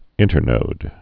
(ĭntər-nōd)